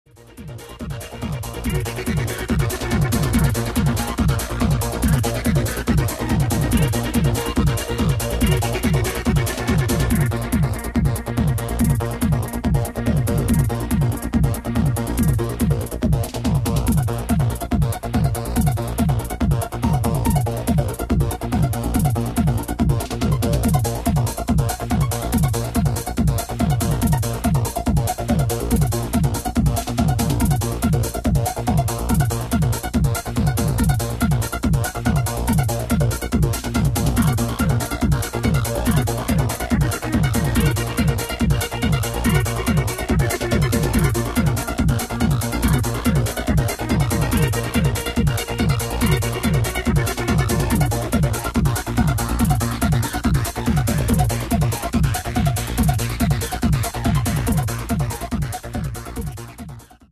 Style: Goa Trance